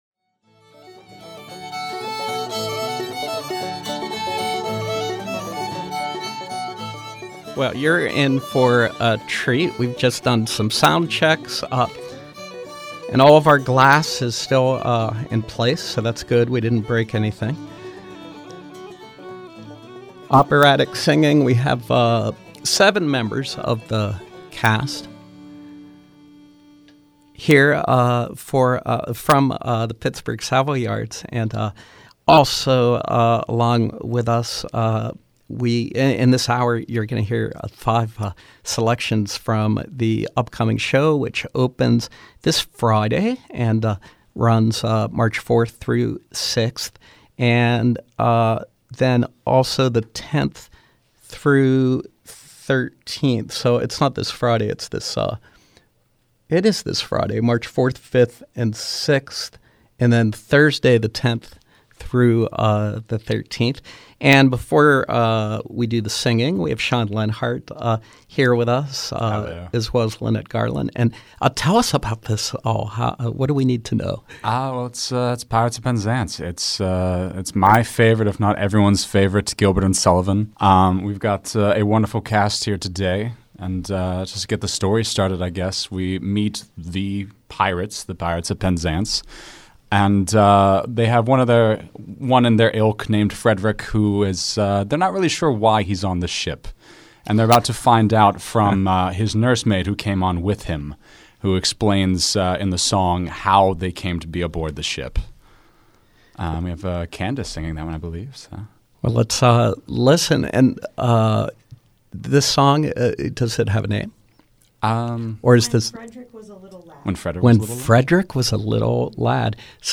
Live Music: Pittsburgh Savoyards
keyboard